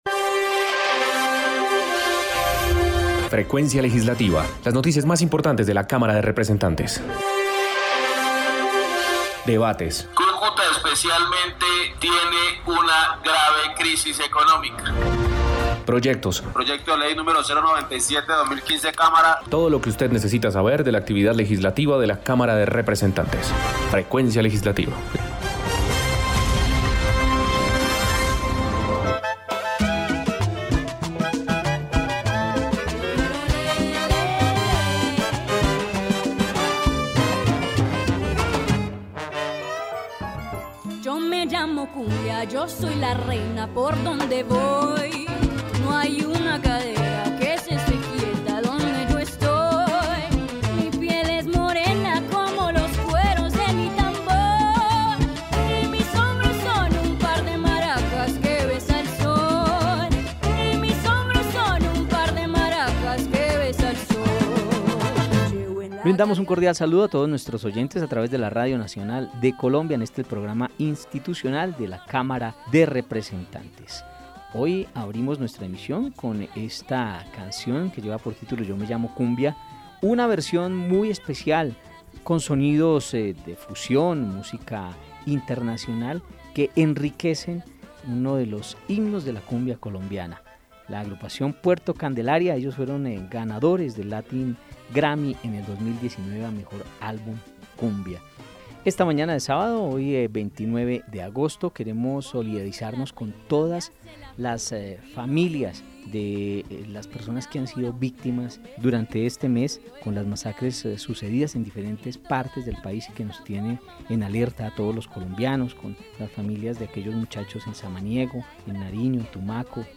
Programa Radial Frecuencia Legislativa. Sábado 29 de Agosto de 2020